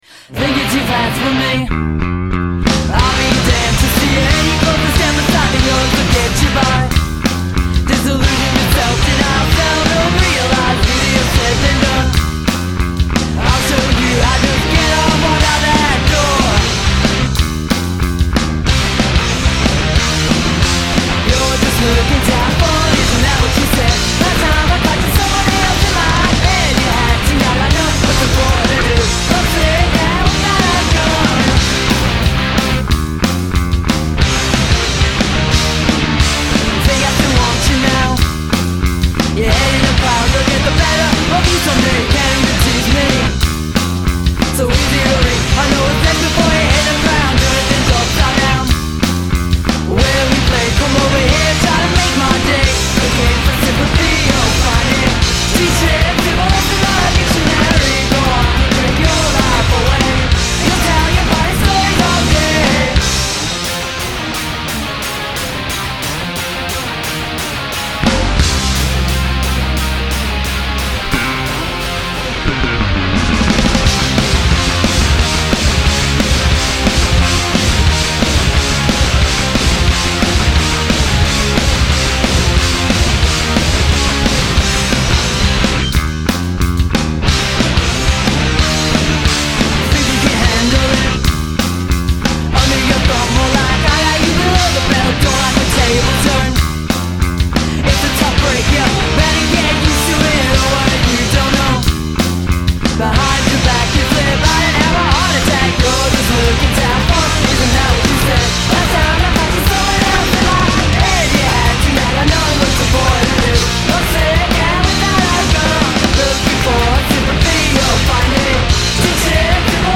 Download Punk Rock cc